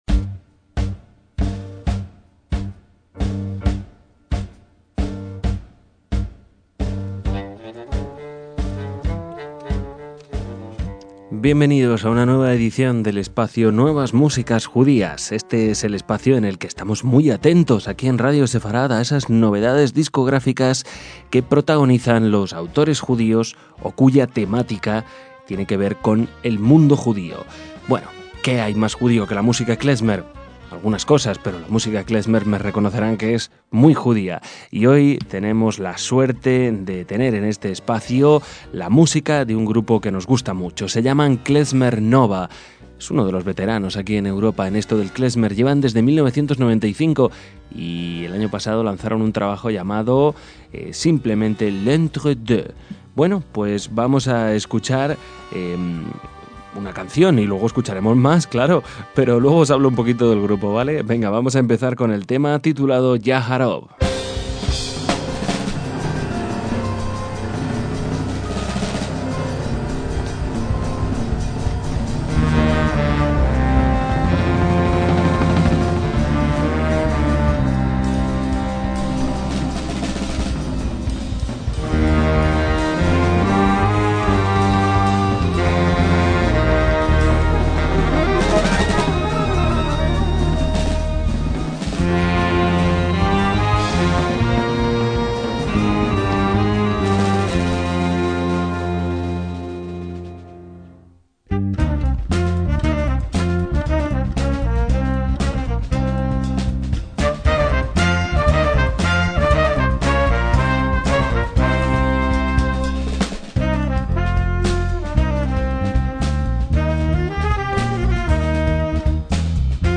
música klezmer
en bajo
a la batería
en piano
con el trombón
con la trompeta
al violín
con los clarinetes